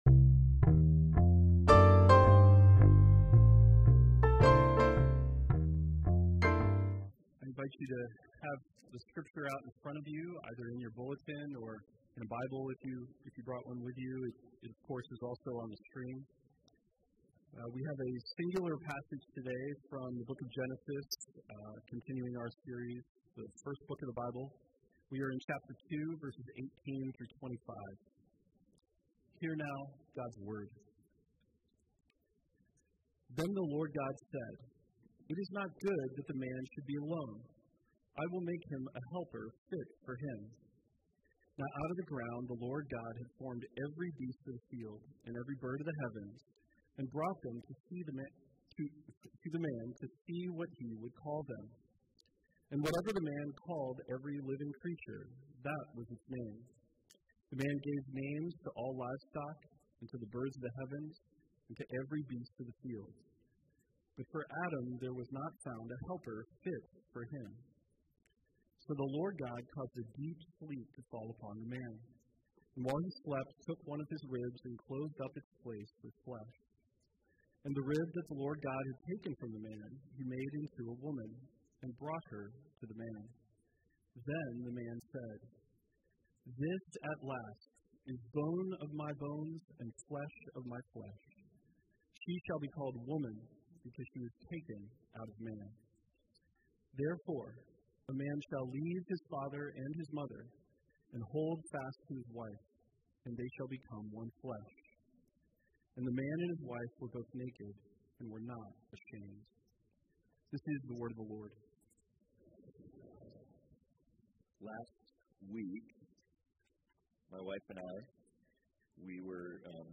Passage: Genesis 2:18-25 Service Type: Sunday Worship